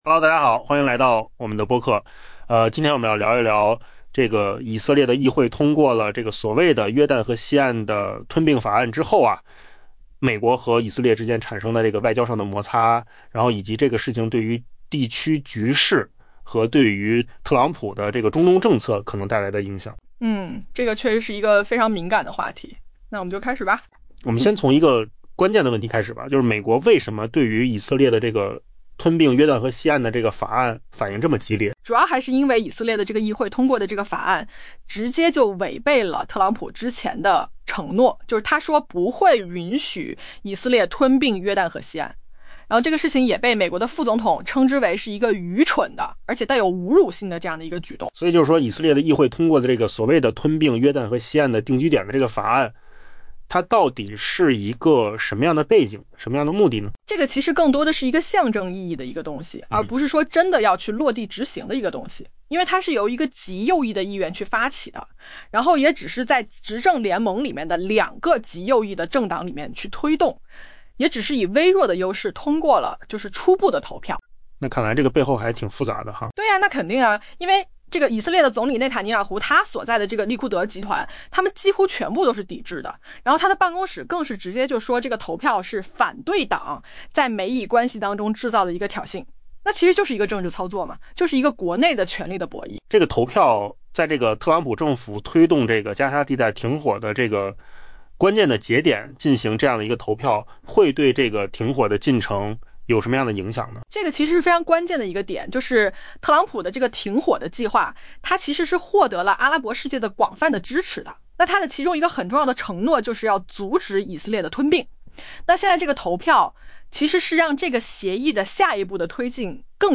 AI 播客：换个方式听新闻 下载 mp3 音频由扣子空间生成 美国副总统 JD·万斯 （JD Vance） 斥责以色列议会通过一项将主权延伸至约旦河西岸的法案，称其 「愚蠢且具侮辱性」 ——此举公然违背了美国总统特朗普此前的承诺。